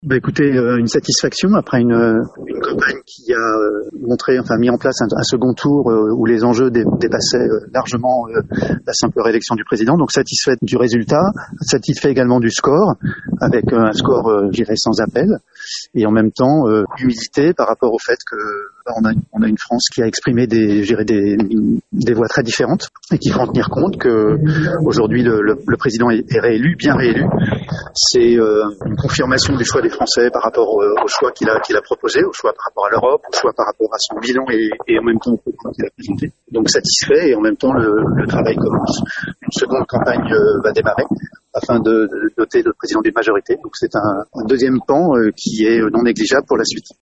On écoute la réaction de Christophe Plassard, conseiller municipal à Royan et candidat à l’investiture de la majorité présidentielle sur la 5e circonscription de la Charente-Maritime :